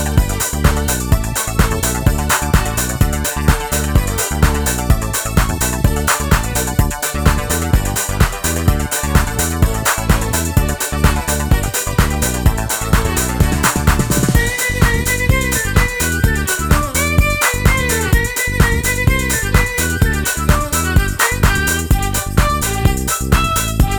no Backing Vocals Irish 3:57 Buy £1.50